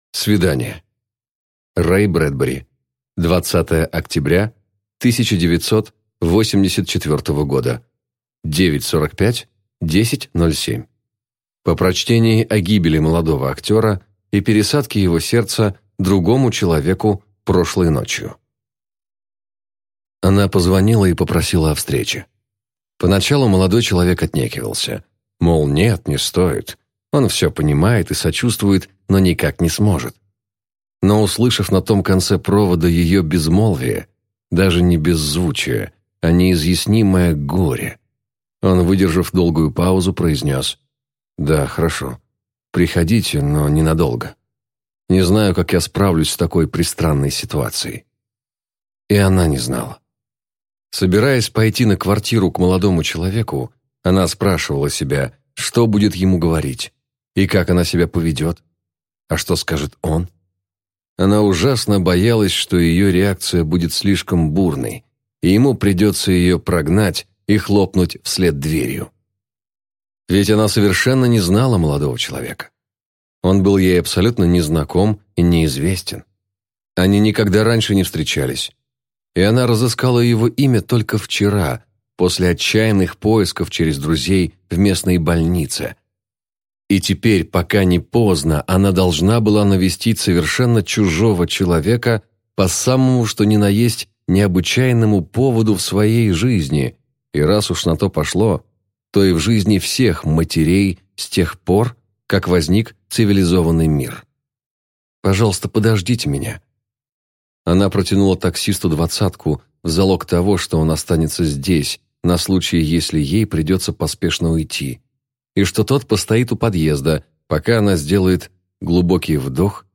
Аудиокнига У нас всегда будет Париж | Библиотека аудиокниг